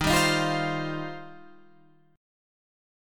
Eb6add9 chord